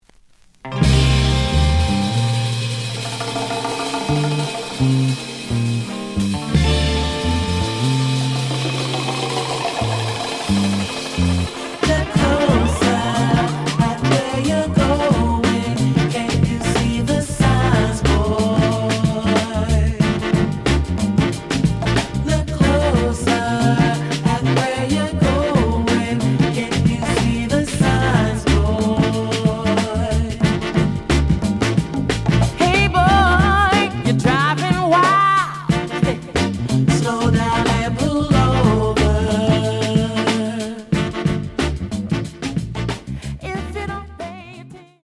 The audio sample is recorded from the actual item.
●Genre: 2000's ~ Soul